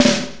Index of /90_sSampleCDs/Northstar - Drumscapes Roland/DRM_R&B Groove/SNR_R&B Snares x